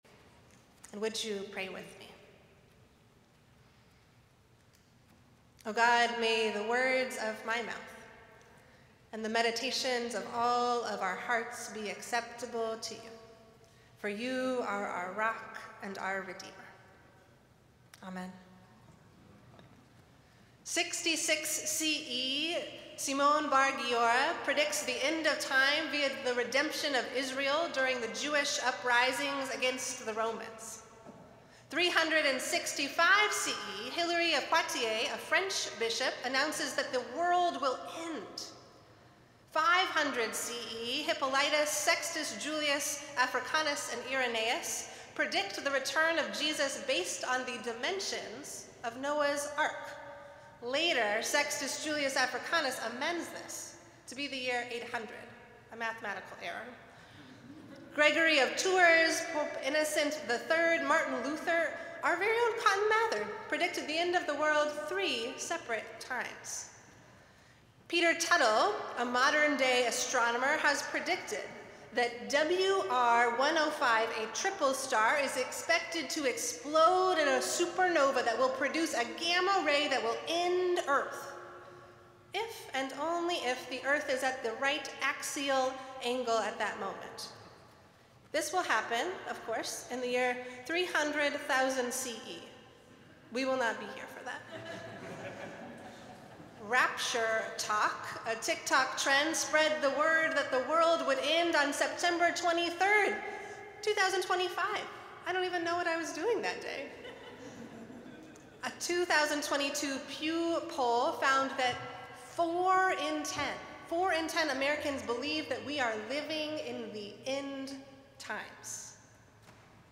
Festival Worship - Second Sunday in Advent